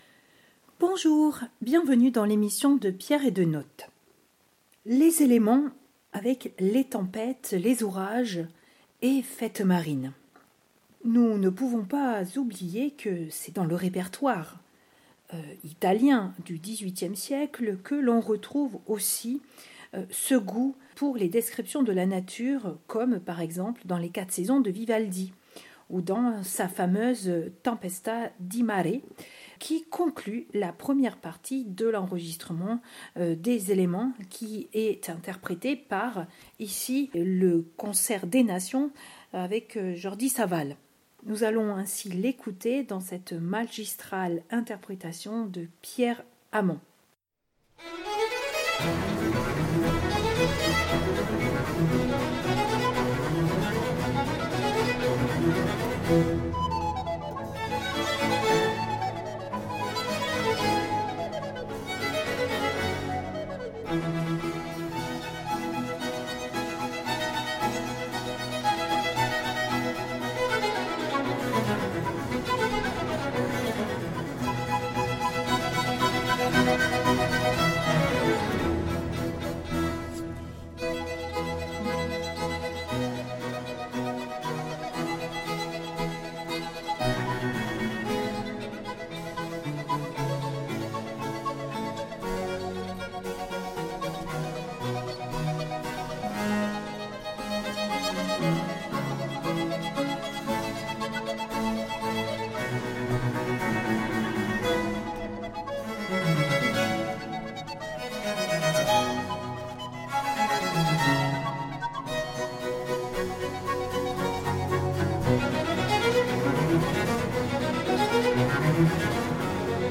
Concerto en Fa Maj. La Tempesta di Mare - F. 6 n° 12 de Vivaldi et de la wassermusick de Tellemann interpréter par le concert des nations :